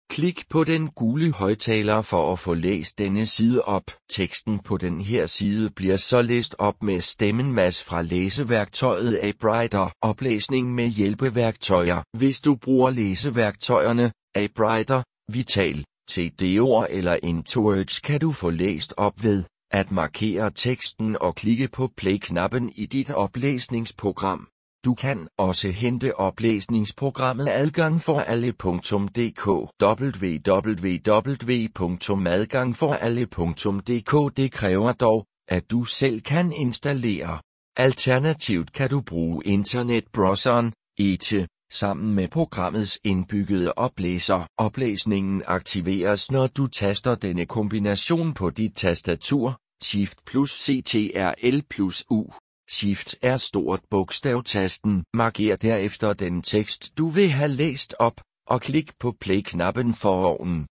Oplæsning på hjemmesiden
Teksten på den her side bliver så læst op med stemmen Mads fra læseværktøjet AppWriter.